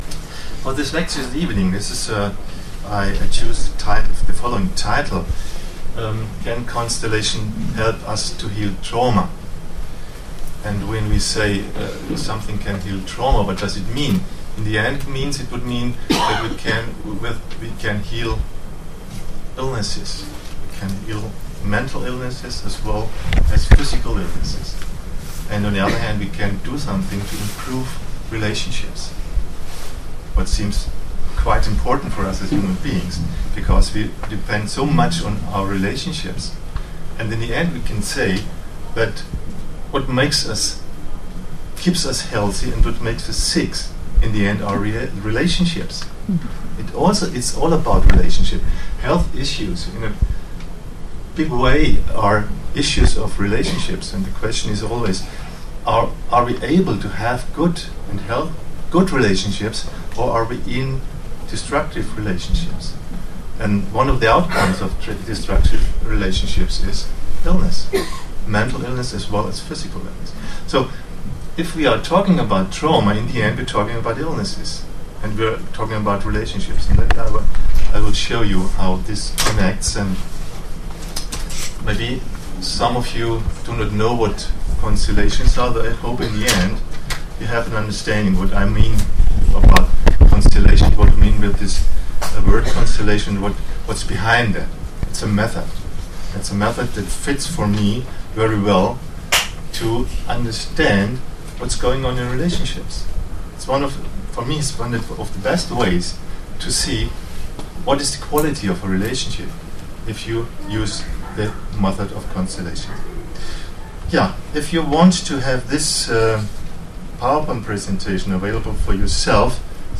Oslo lecture.